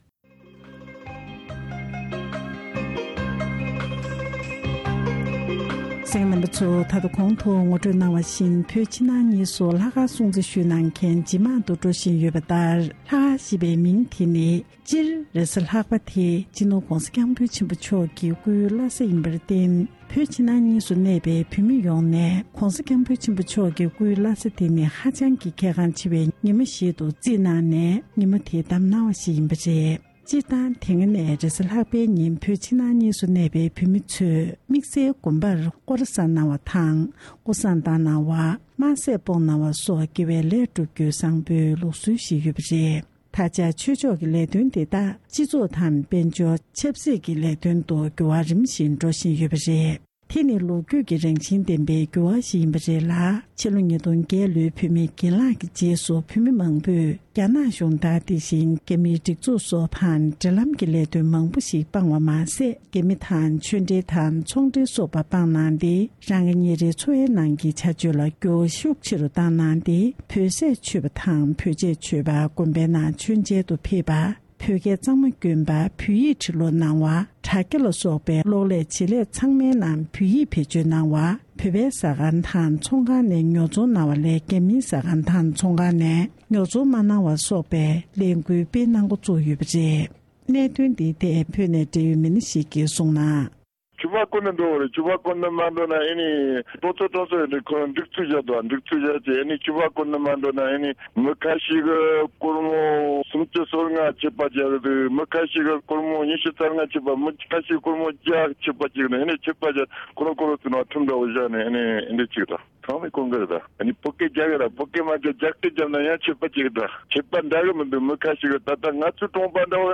ལྷག་དཀར་སྐོར་འབྲེལ་ཡོད་མི་སྣར་གནས་འདྲི་ཕྱོགས་སྒྲིག་ཞུས་པ་ཞིག་གསན་རོགས་གནང་།།